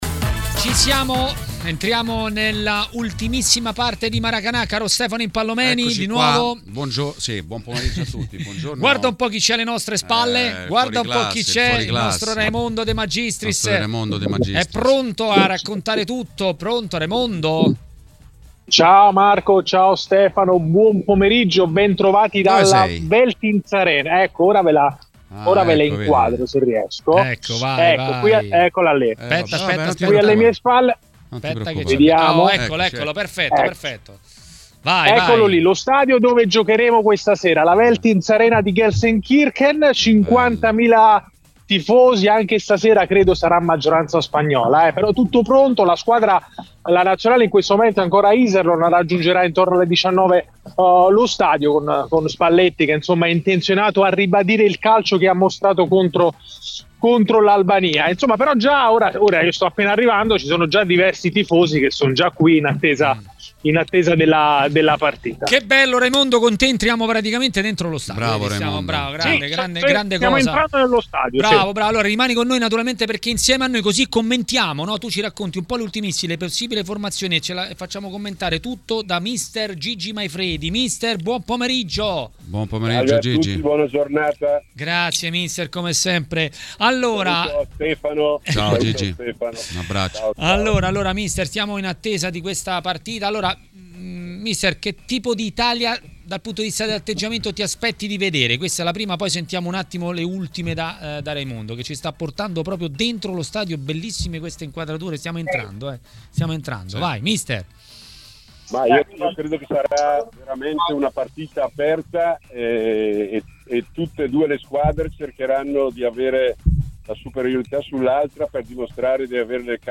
Mister Gigi Maifredi a TMW Radio, durante Maracanà, ha parlato di Nazionale.